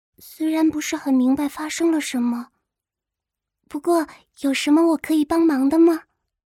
Game VO
Her voice carries an elegant, almost theatrical flair—each syllable meticulously polished to reflect her ladylike exterior demeanor.
Her soft, vacuous yet ethereal tone seems to drift from distant clouds—an otherworldly quality that vividly portrays an introverted and fragile young girl.